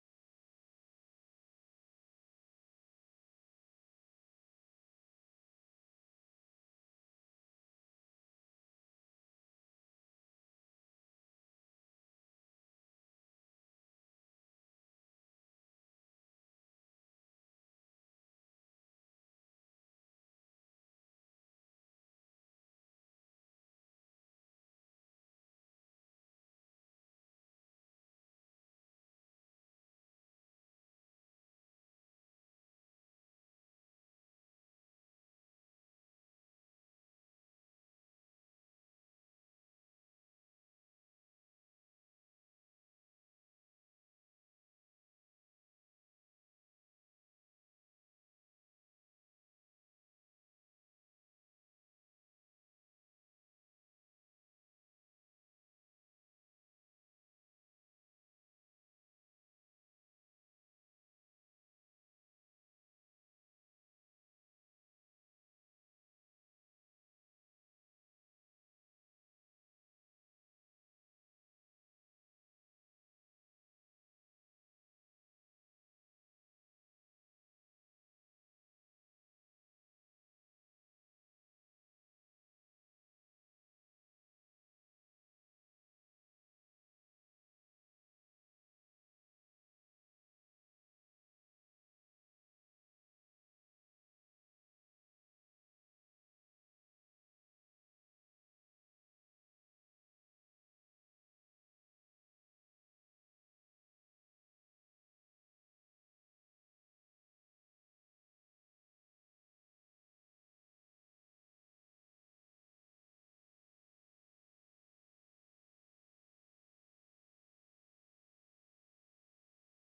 محاضرة - التعليق على رسالة تفسير سورة النصر لابن رجب الحنبلي (1445هـ)